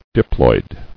[dip·loid]